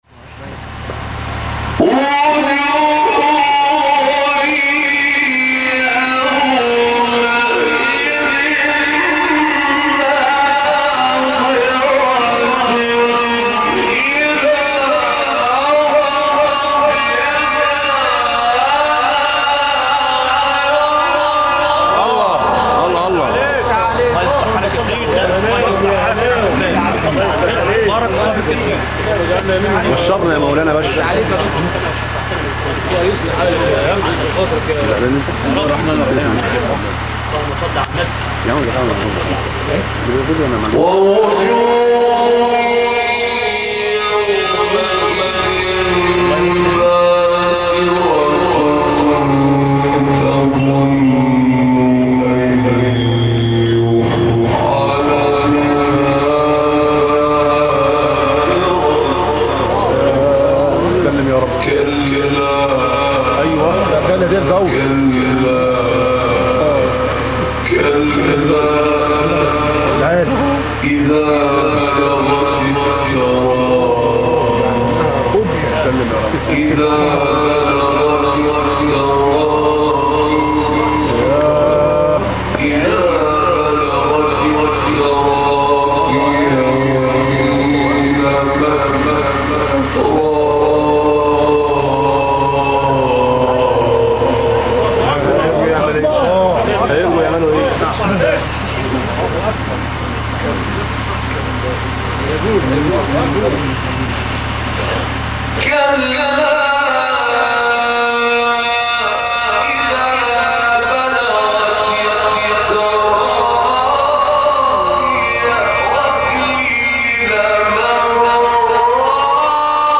سوره : قیامه آیه: 22-40 استاد : عبدالفتاح طاروطی مقام : صبا قبلی بعدی